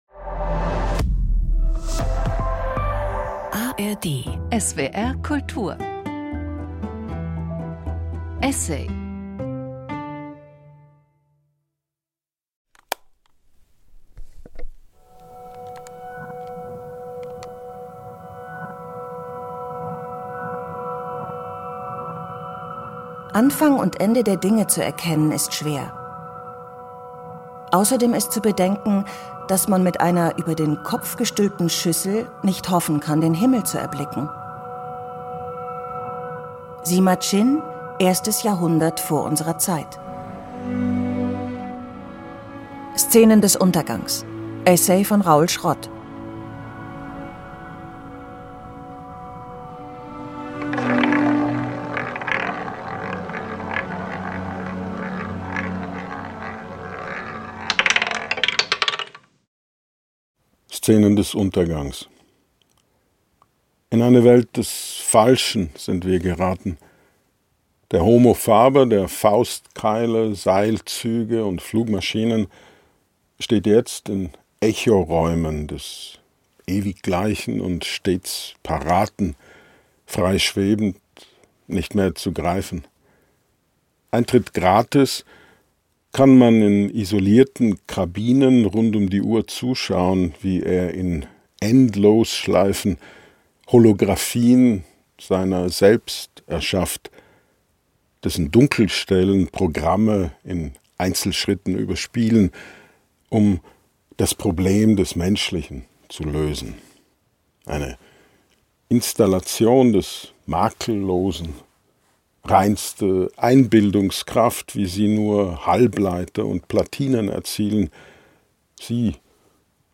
Für den SWR kommentiert Raoul Schrott exklusiv die Gedichte, erzählt von ihrem Entstehen und davon, wie Orte und Worte zusammenhängen.